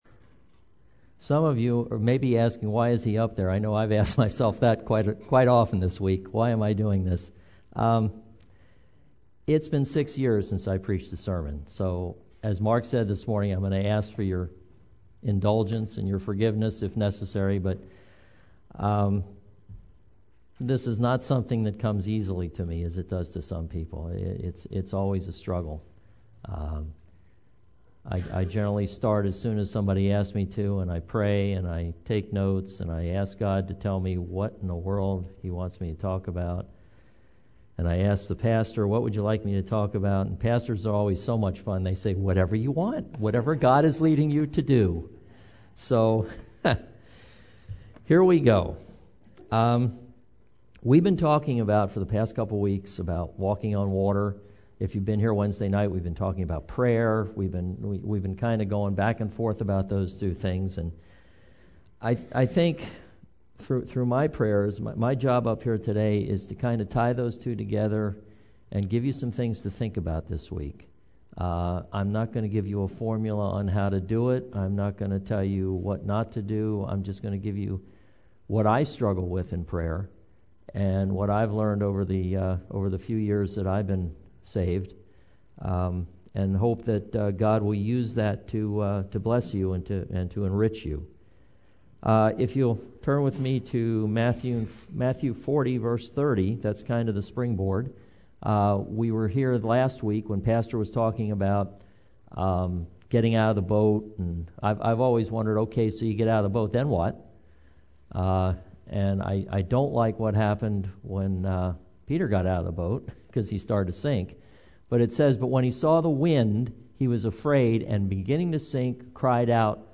In today's sermon